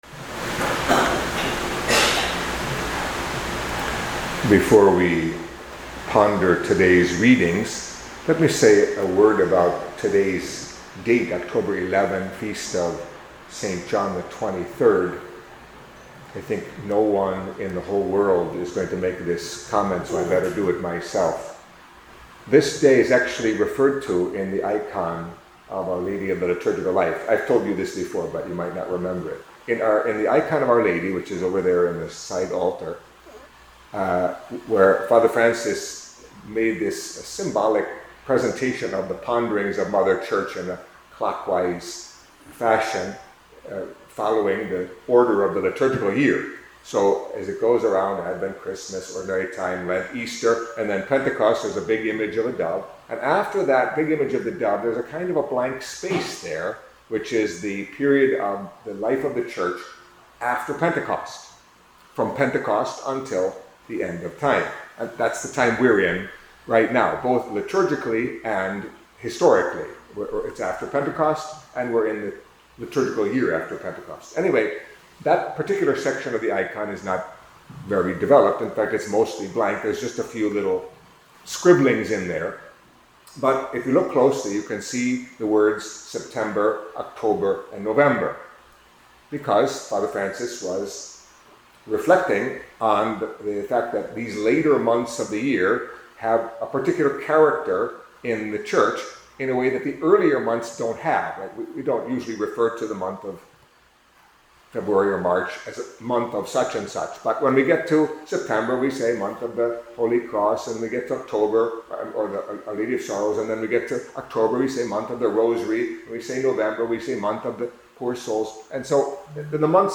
Catholic Mass homily for Wednesday of the Twenty-Seventh Week in Ordinary Time